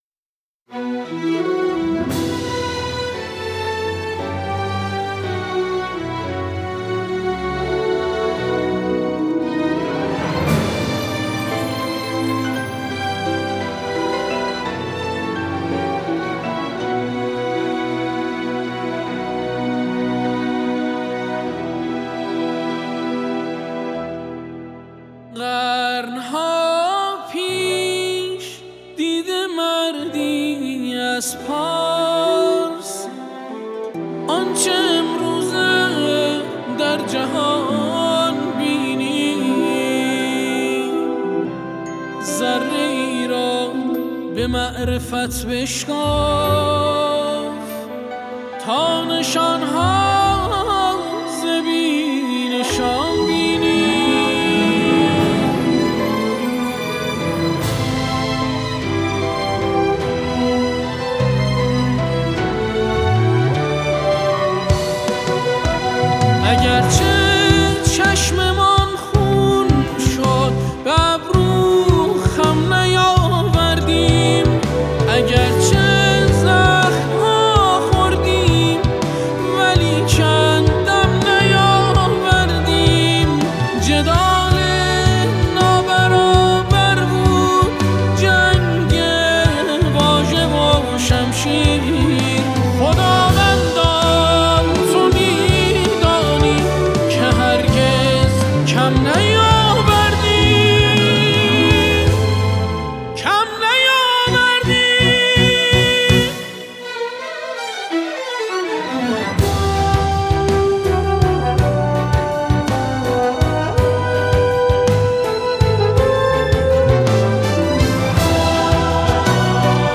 گروه کُر